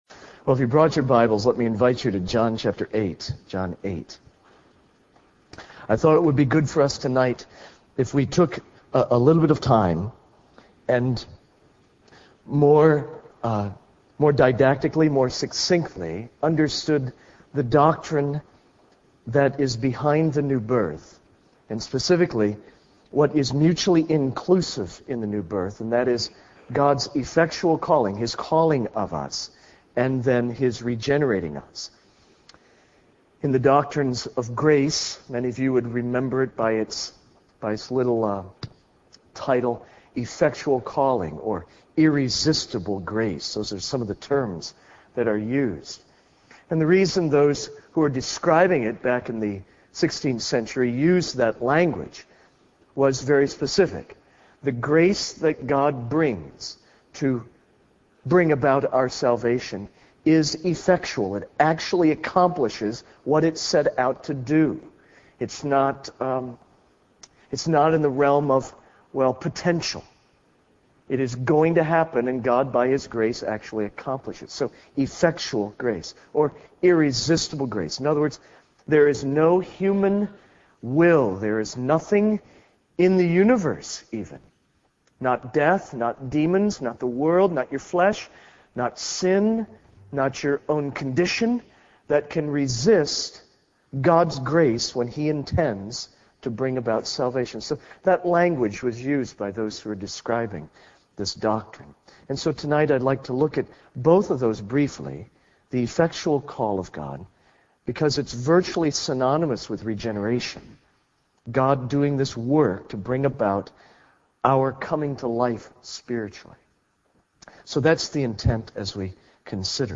Sermons from 2007